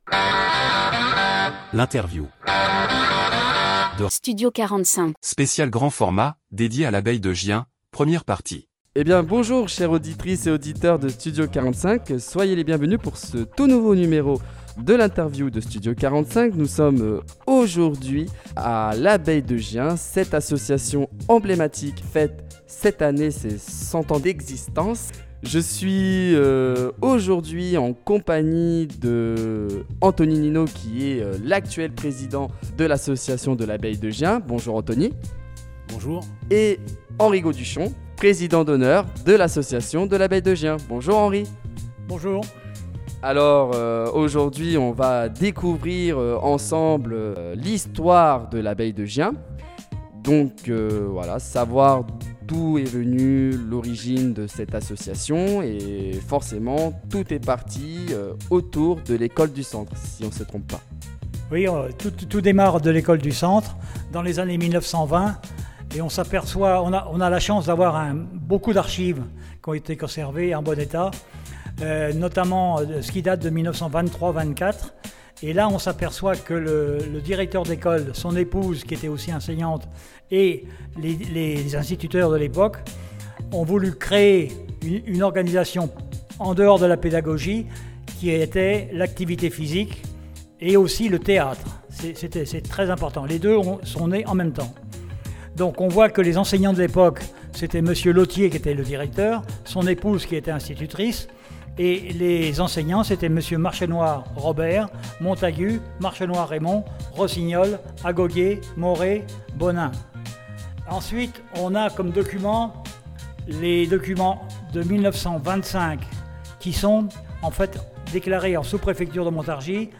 Interview Studio 45 - Les 100 ans de l’Abeille de Gien – Partie 2